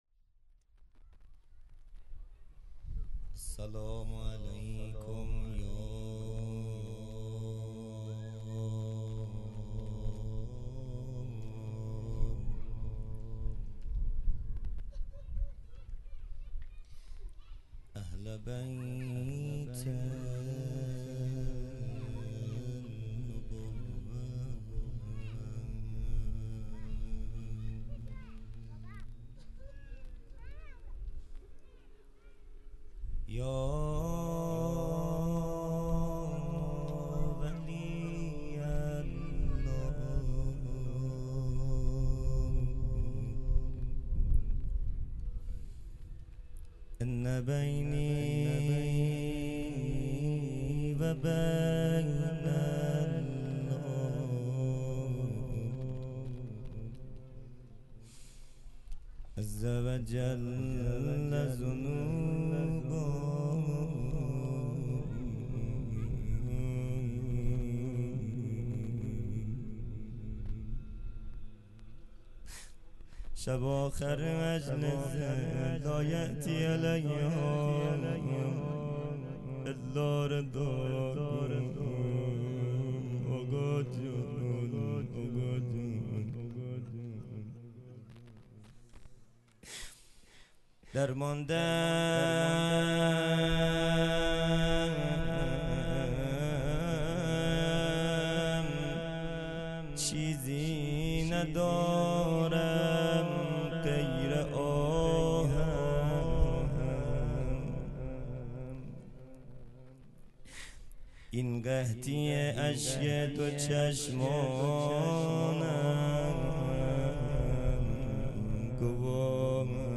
خیمه گاه - هیئت بچه های فاطمه (س) - روضه | درمانده ام چیزی ندارم